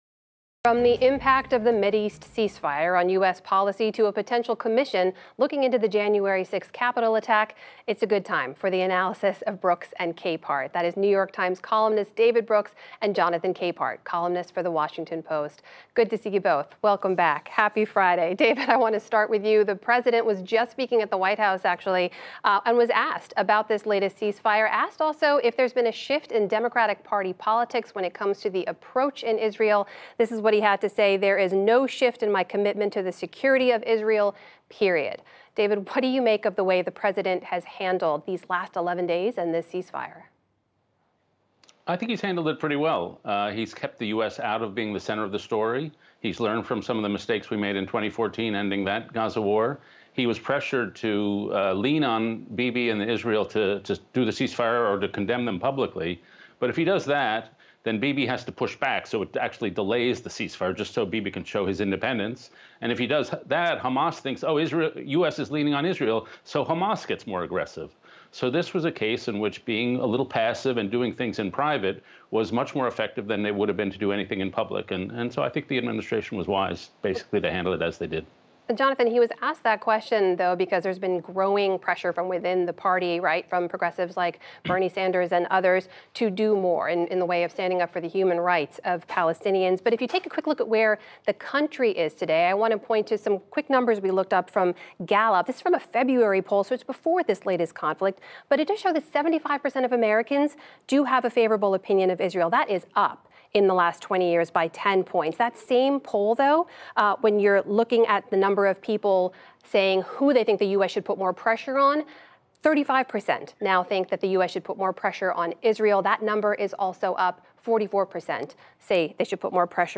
Listen to David Brooks and Jonathan Capehart analyze the political news of the week. Posted each Friday by 9 p.m., the Brooks and Capehart podcast includes the full audio of every on-air segment.